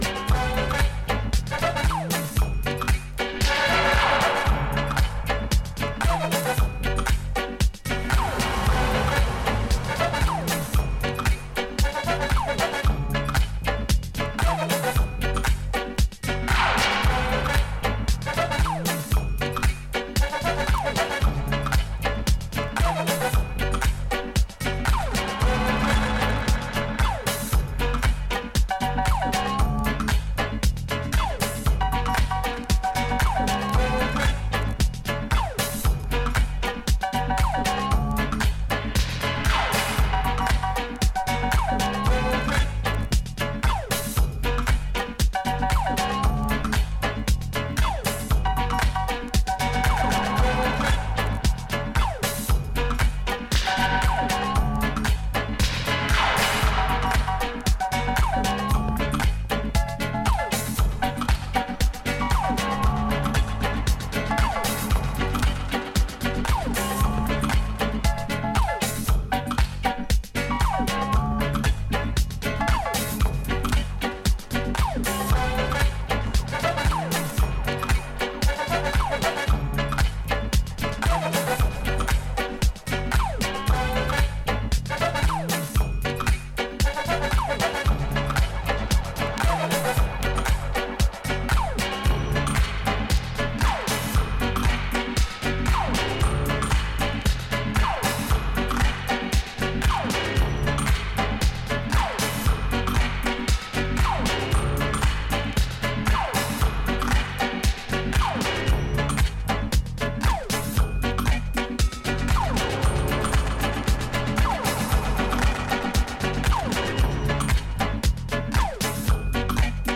ここでは、シンセ・ポップやアフロ・トロピカル路線の音源をネタに用いながらゆるくてダビーなバレアリック・トラックを展開。